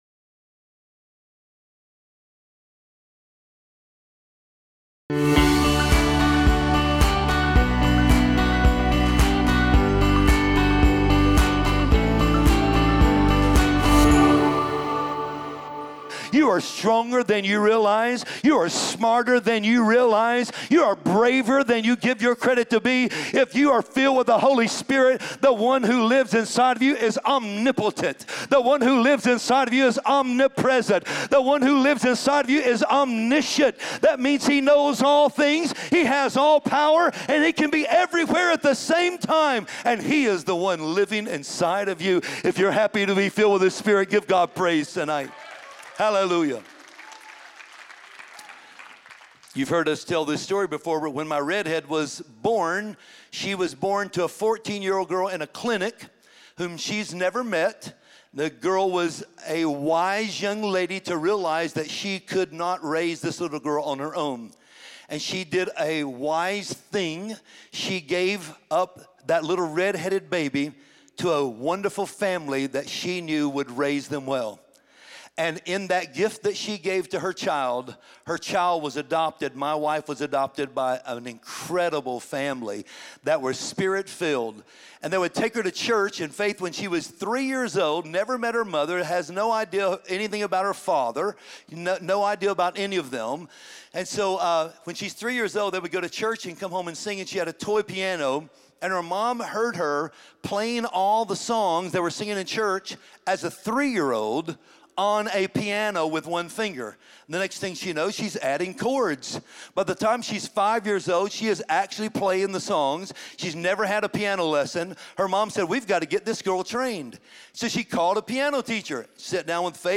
Made to Make a Difference sermon